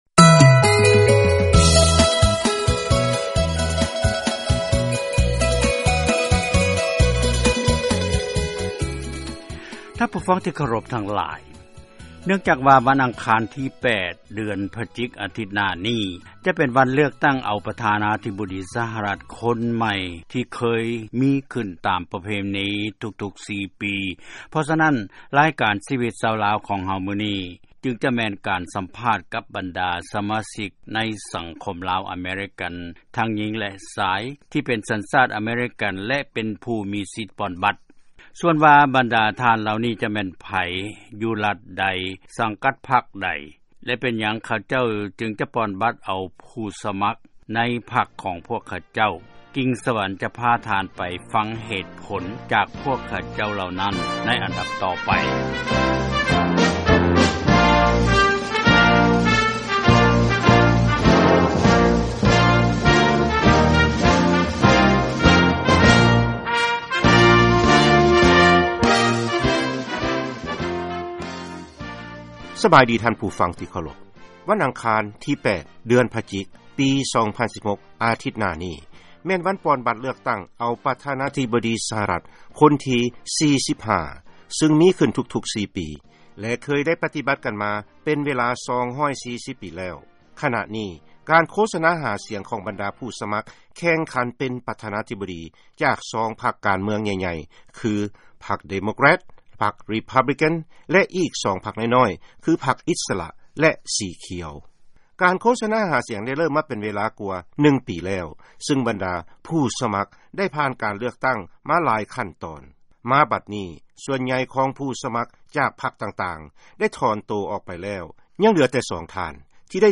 ເຊີນຟັງການສຳພາດ ບັນດາອາເມຣິກັນ-ເຊື້ອສາຍລາວ ກຽມປ່ອນບັດເລືອກຕັ້ງ ປະທານາທິບໍດີສະຫະລັດ